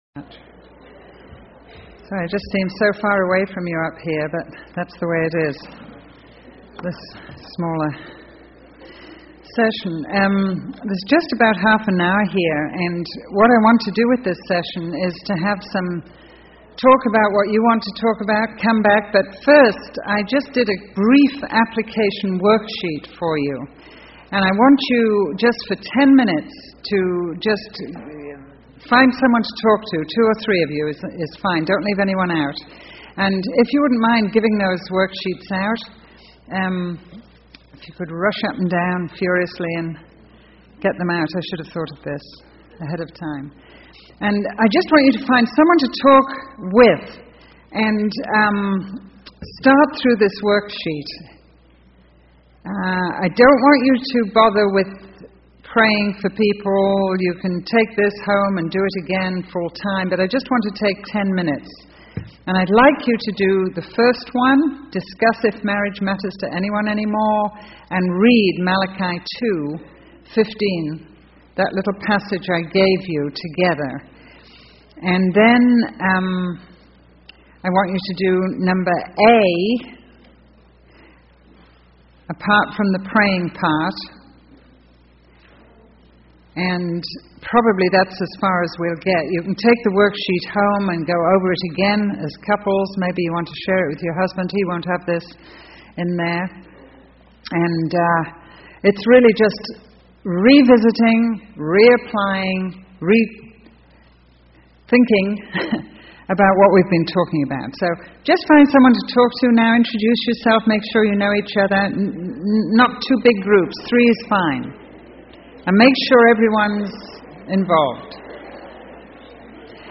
Session 3: Women's Q & a (Couples Conference)